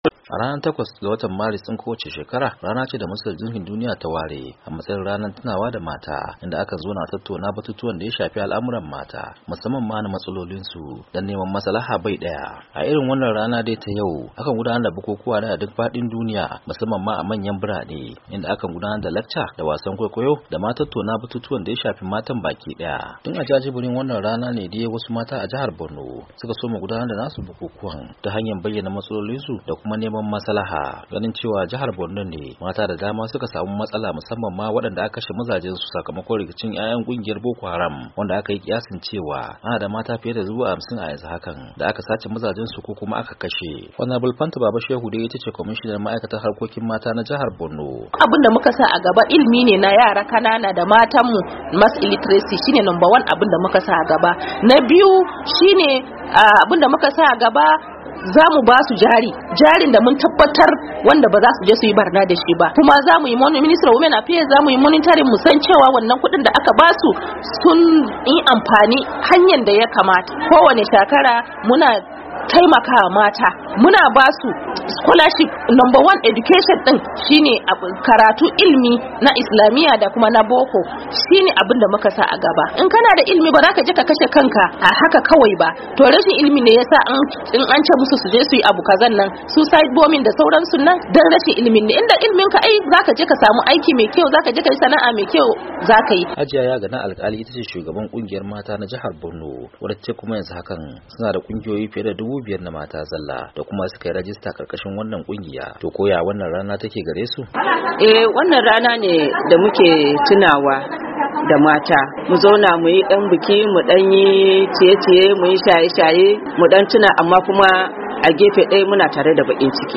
Matan da aka zanta dasu sun ce suna bikin ranar tunawa da mata da farin ciki amma kuma da bakin ciki a wani gefen saboda wasu cikinsu da suka rasa mazajensu.